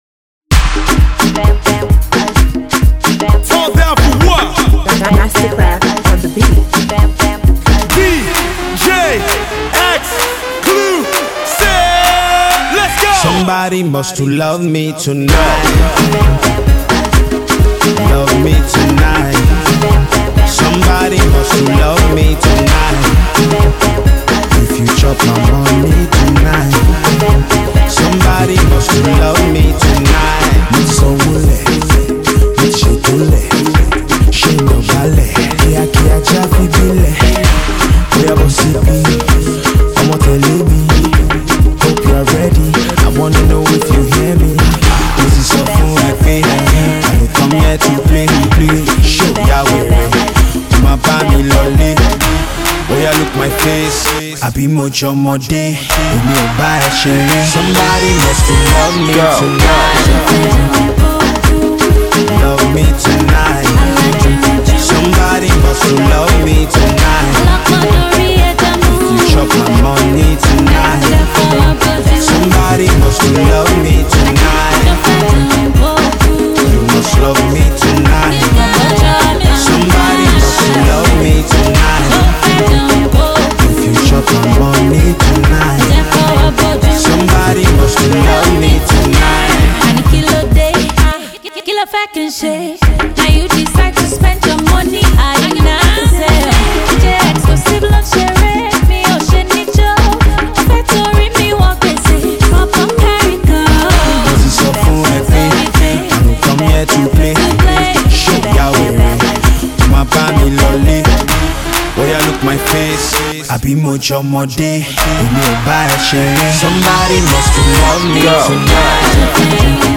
AudioPopVideo